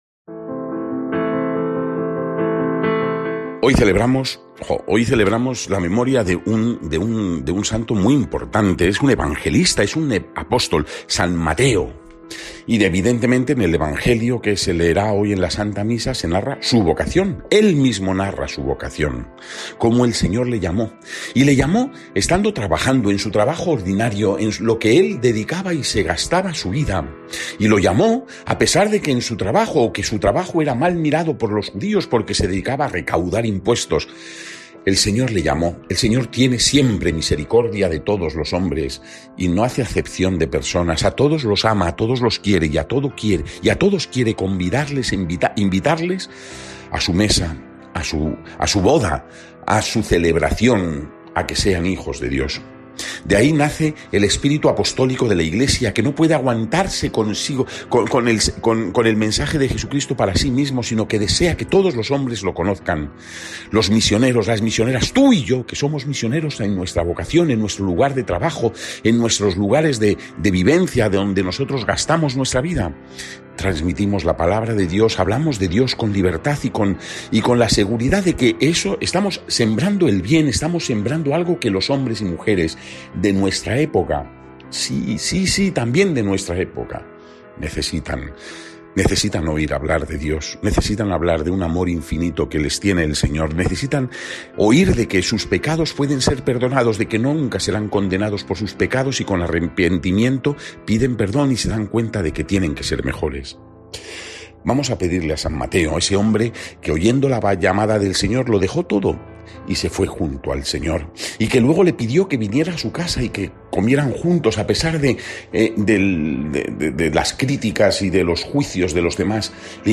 Evangelio del día